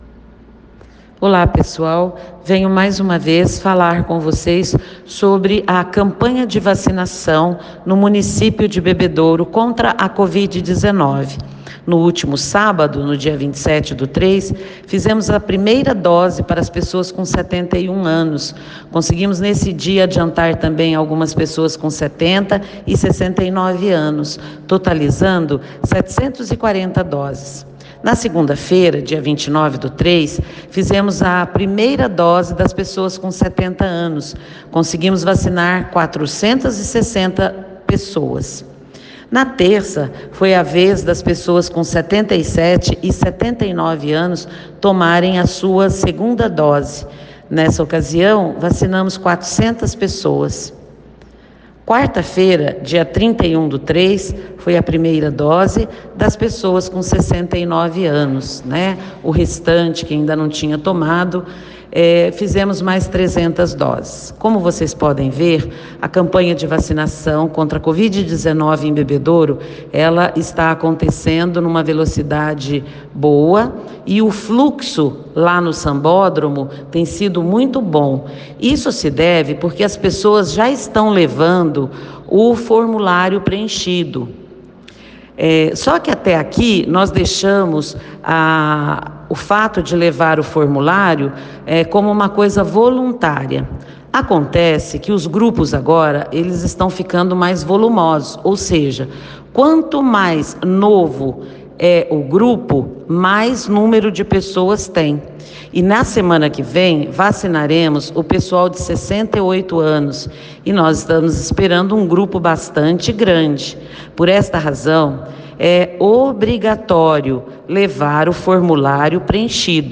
A secretária de saúde, Silvéria Maria Peixoto Laredo, informa detalhes da vacinação em Bebedouro.
Ouça aqui o áudio secretária de saúde.